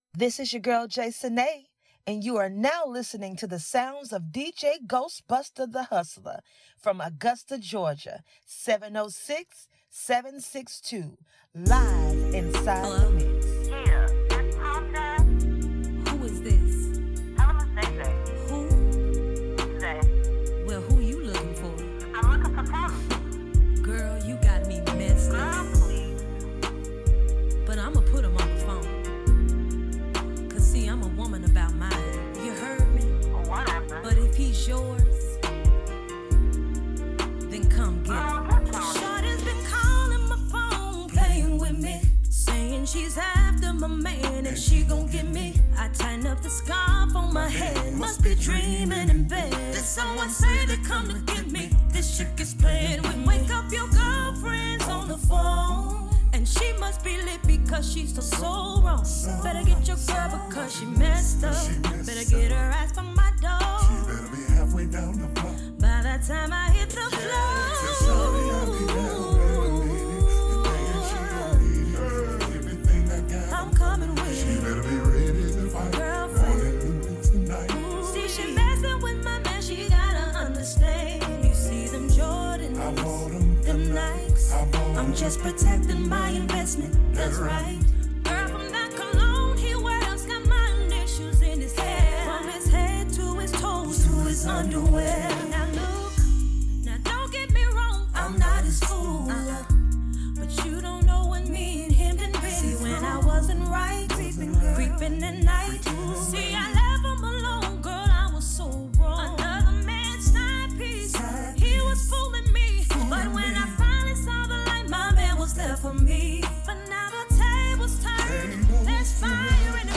RnB
BLAZING NEW SCHOOL BLUES FEMALE ARTIST
Rnb & Crunk Mix